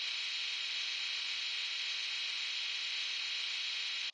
buzz_hissy_medium.ogg